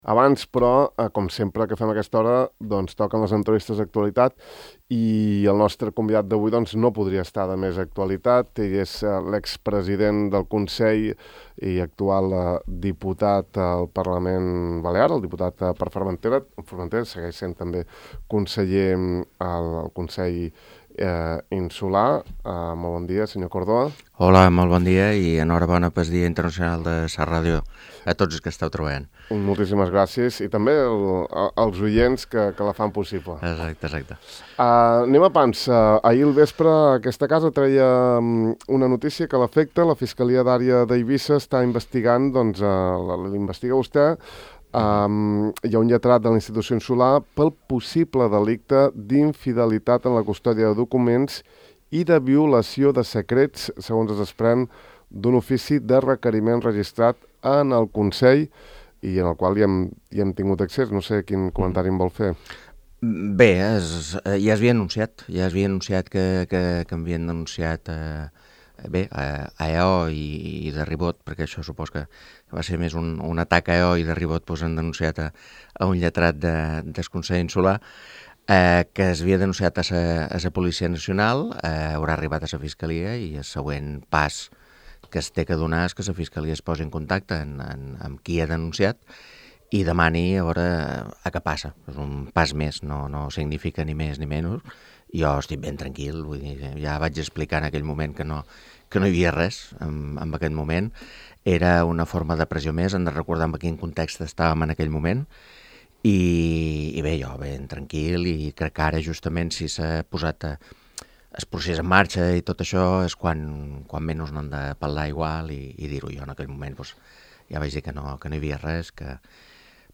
El diputat per Formentera i expresident del Consell, Llorenç Córdoba, ha declarat a Ràdio Illa que està “ben tranquil” i que ja va explicar “que no hi havia res”, després que Ràdio Illa hagi publicat el contingut d’un ofici de requeriment de la Fiscalia d’Àrea d’Eivissa segons el qual el ministeri públic l’investiga per un possible delicte d’infidelitat en la custòdia de documents i de violació de secrets.